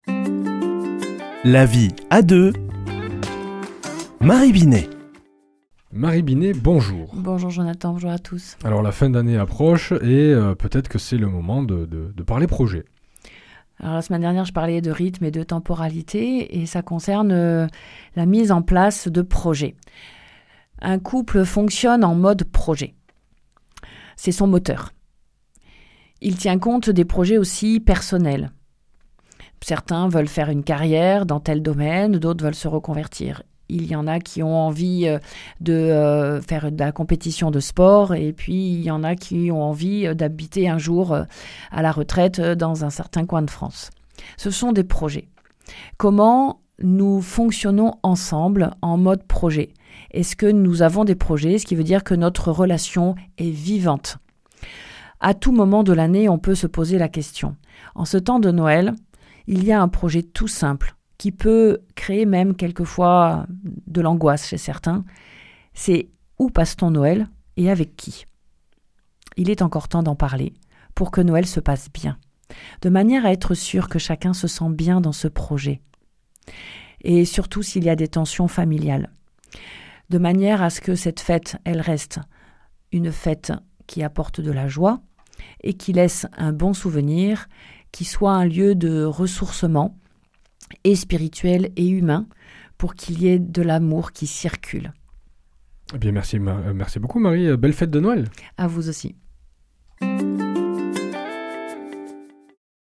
mardi 23 décembre 2025 Chronique La vie à deux Durée 4 min